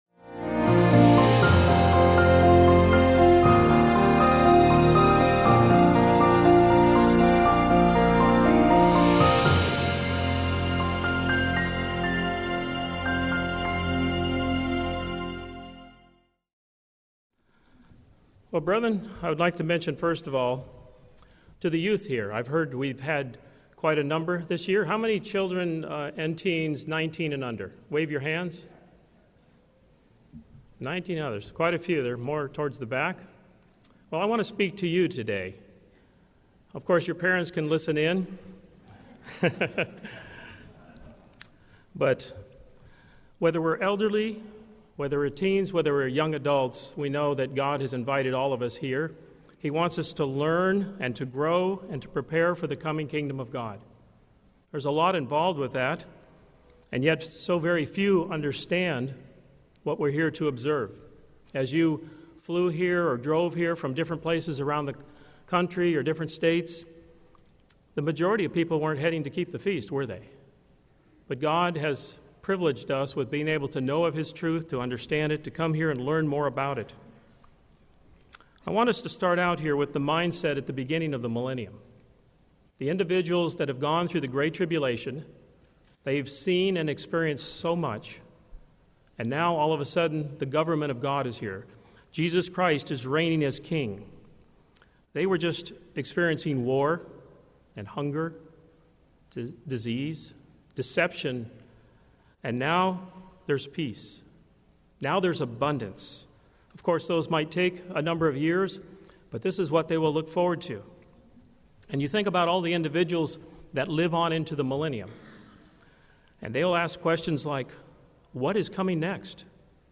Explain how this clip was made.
This sermon was given at the Jekyll Island, Georgia 2019 and Celebrating the Feast of Tabernacles Online 2020 Feast sites.